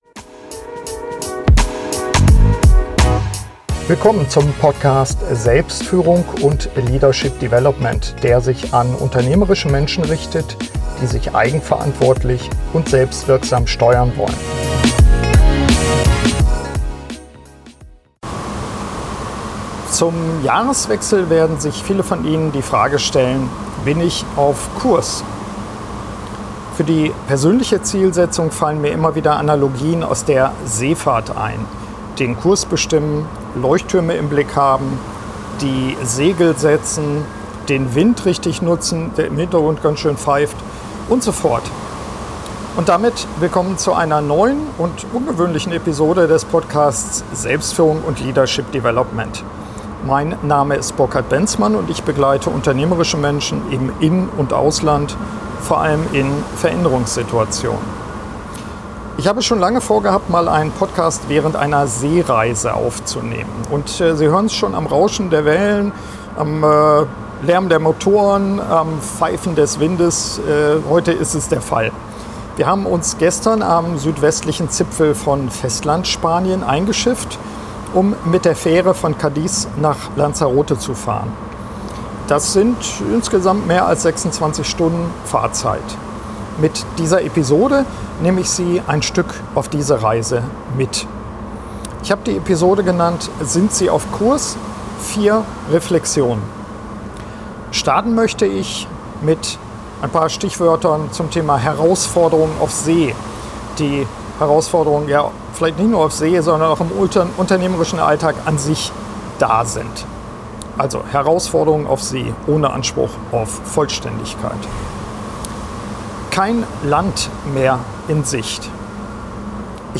Und passender Weise nehme ich die Episode während einer Seereise auf. Gestern haben wir uns am südwestlichen Zipfel von Festlandspanien eingeschifft, um mit der Fähre von Cádiz nach Lanzarote zu fahren.